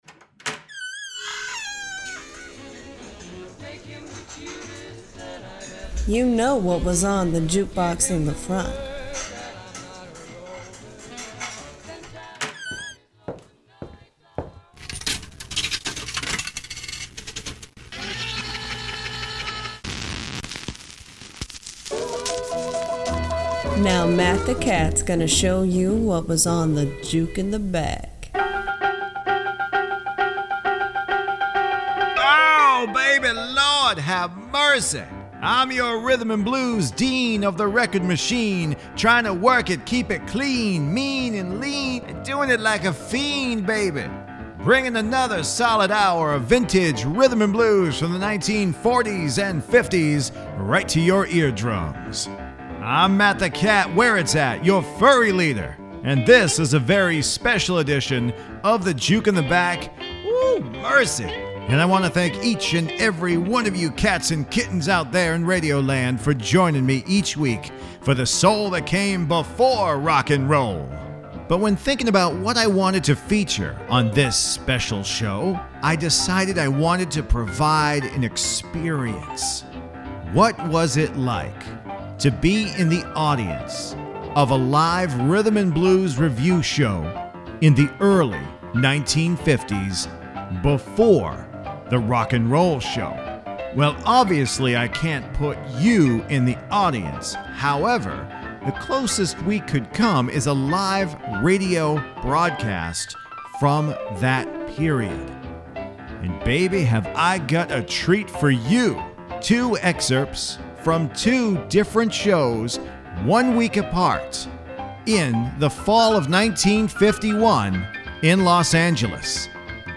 a live rhythm review performance
Gospel group